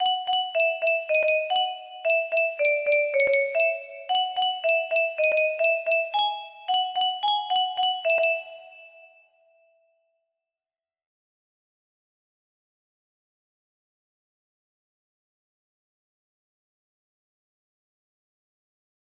TV music box melody